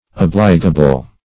Search Result for " obligable" : The Collaborative International Dictionary of English v.0.48: Obligable \Ob"li*ga*ble\, a. Acknowledging, or complying with, obligation; trustworthy.